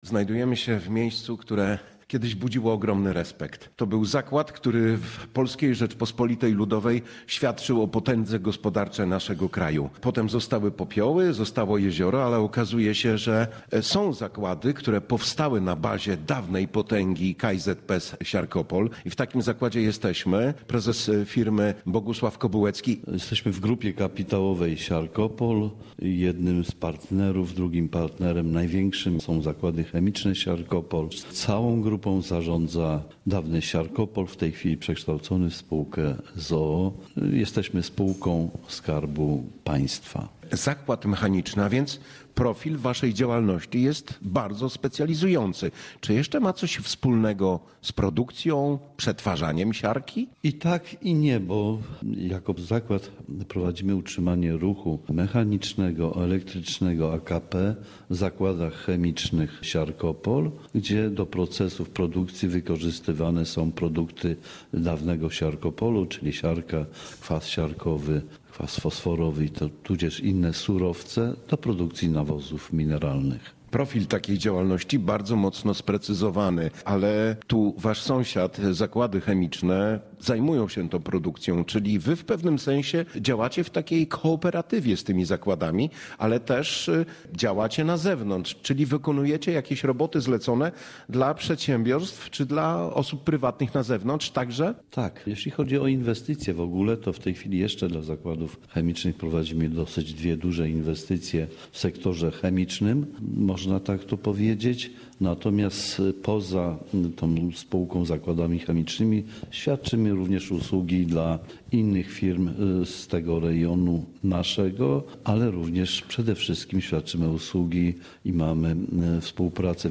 rozmawia z przedstawicielami związków zawodowych oraz kierownictwem